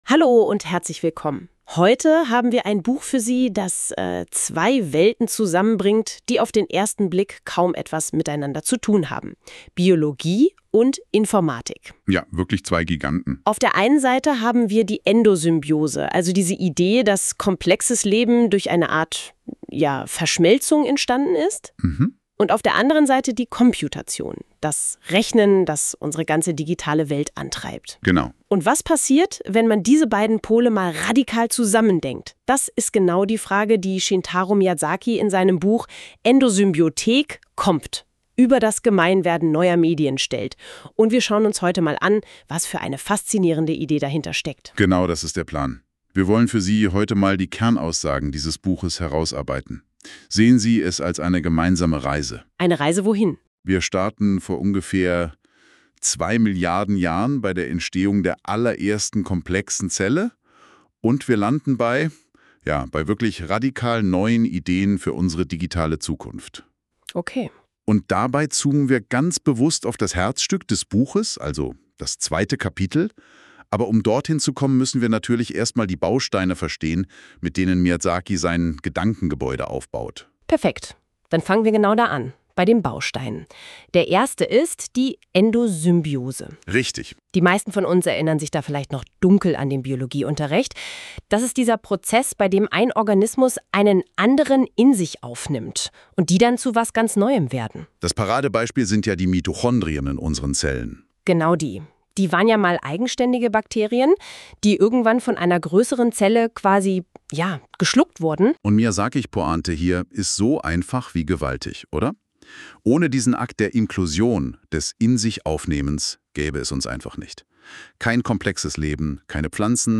Endosymbiotische Komputation – eine maschinengenerierte Buchbesprechung (konkrete Beispiele). Die kleinen Glitches wirken selbstreflektiv auf die Produktionsbedingungen des Gesprächs.